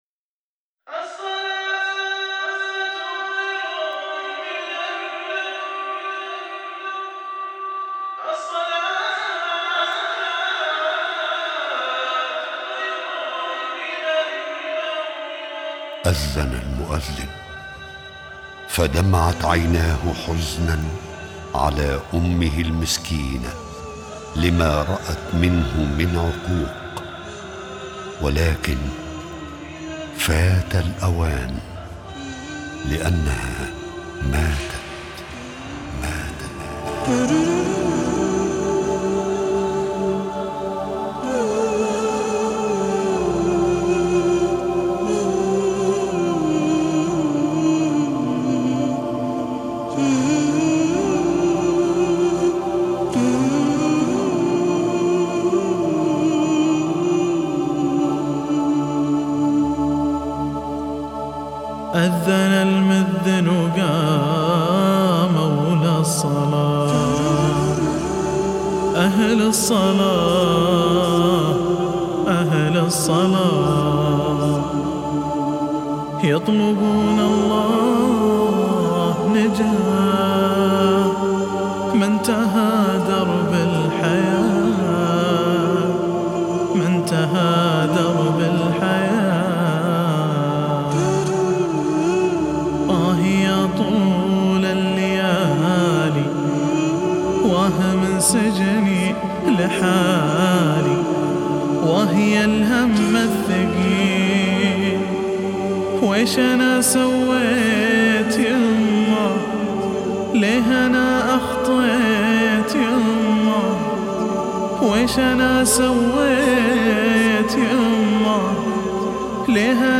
أنشودة
بدقه عاليه